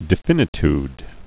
(dĭ-fĭnĭ-td, -tyd)